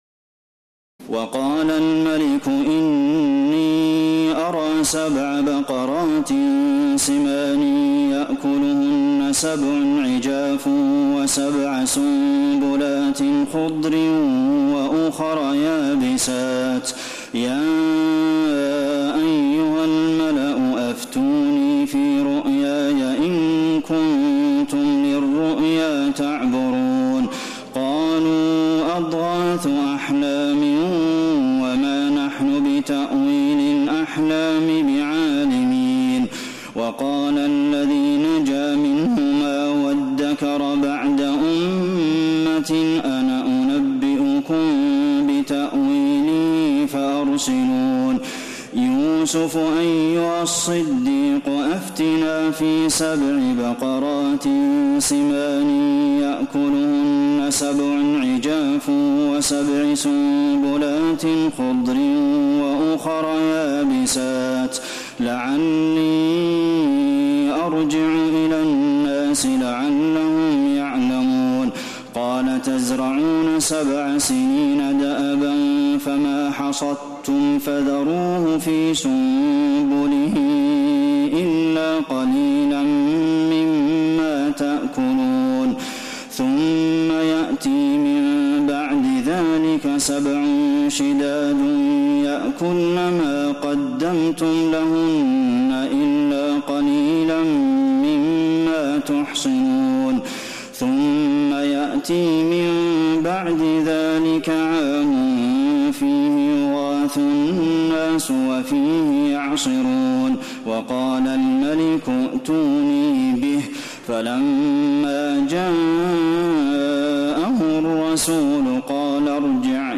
تراويح الليلة الثانية عشر رمضان 1432هـ من سورة يوسف (43-111) Taraweeh 12 st night Ramadan 1432H from Surah Yusuf > تراويح الحرم النبوي عام 1432 🕌 > التراويح - تلاوات الحرمين